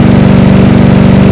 f350_mid.wav